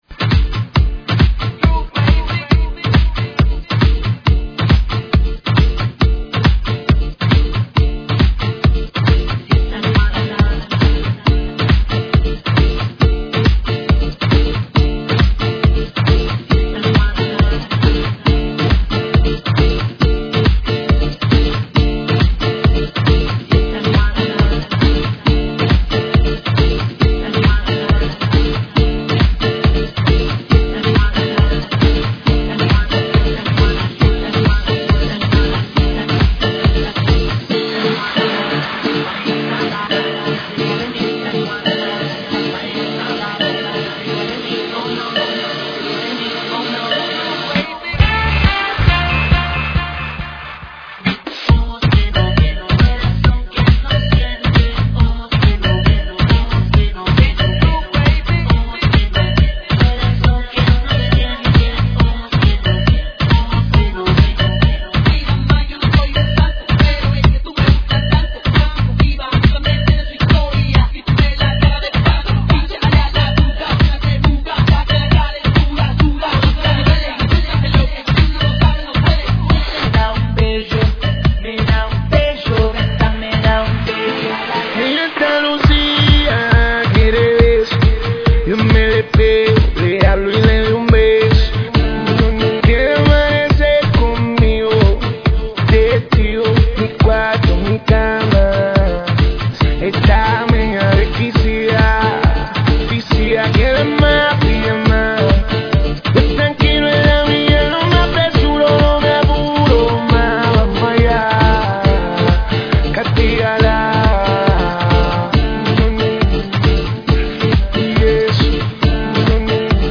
GENERO: RADIO – REMIX
AEROBICS (STEP-HILOW)